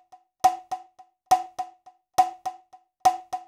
HI COWCONG-R.wav